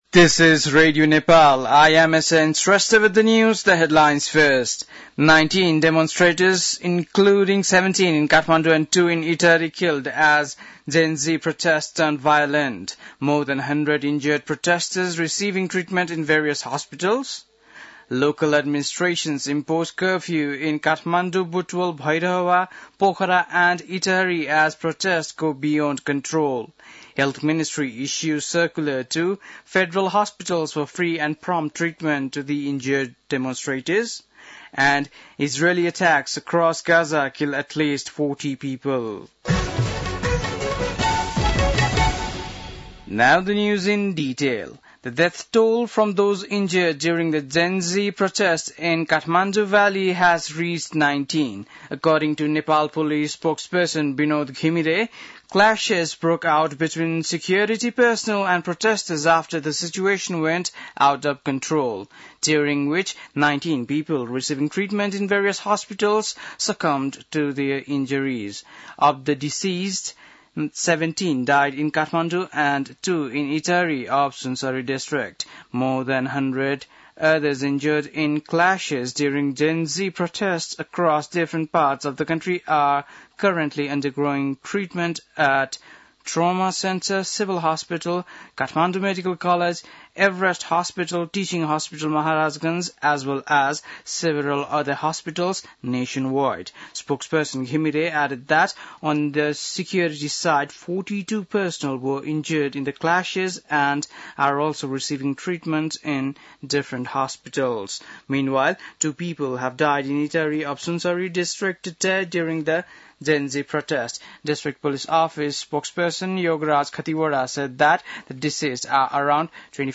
बेलुकी ८ बजेको अङ्ग्रेजी समाचार : २३ भदौ , २०८२
8-PM-English-NEWS-05-23.mp3